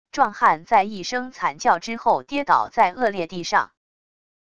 壮汉在一生惨叫之后跌倒在恶劣地上wav音频